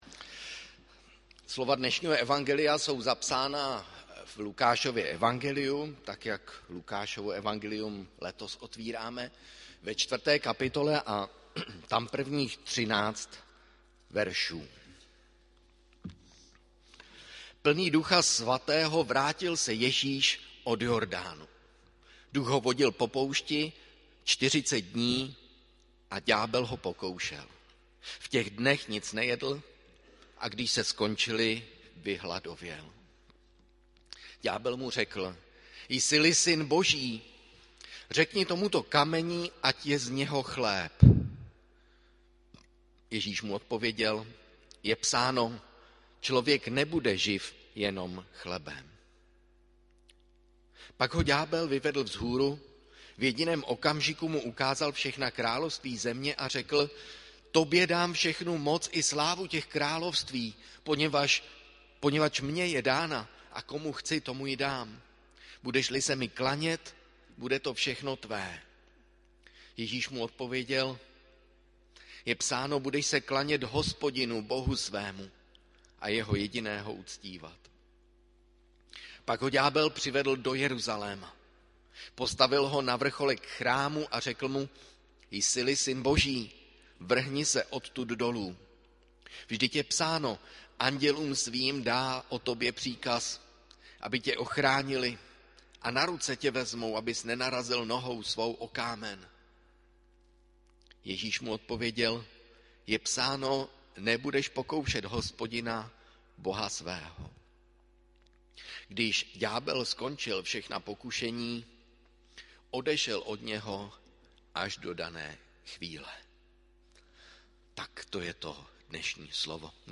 Bohoslužby se slavením sv. Večeře Páně.
audio kázání